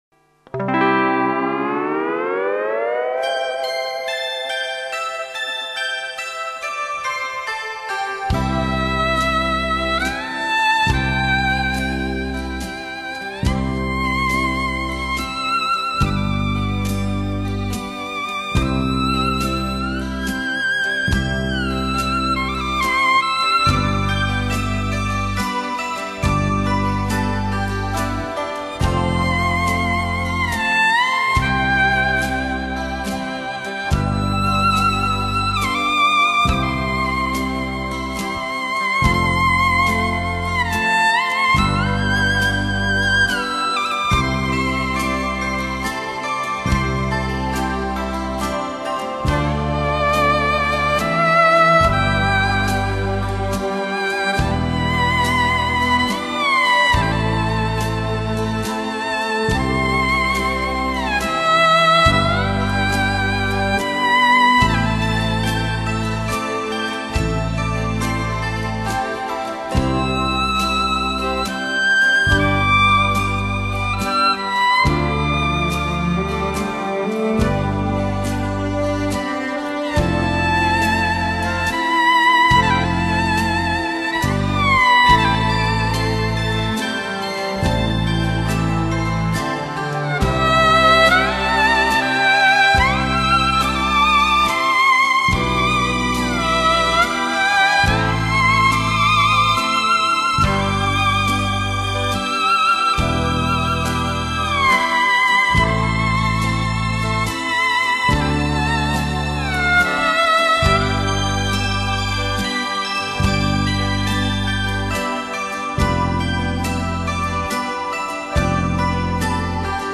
(慢三)